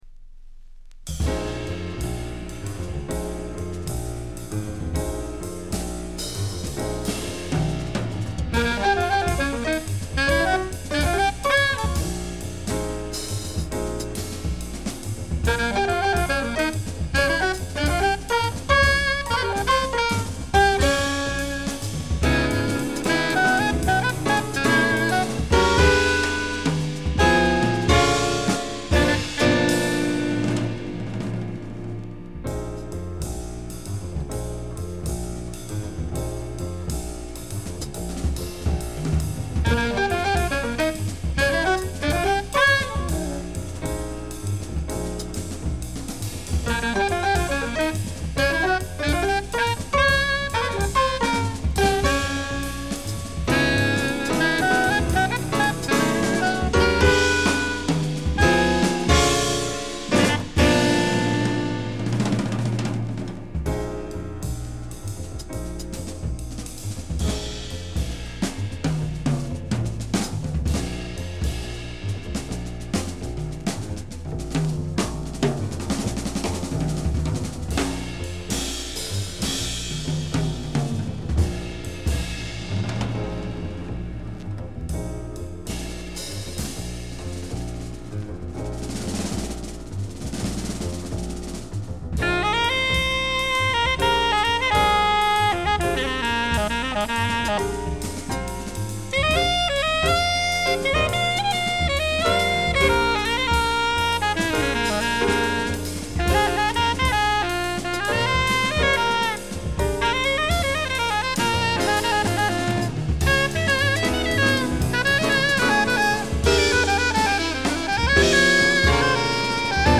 Geneva, Aquarius Studio - 1977
sax alto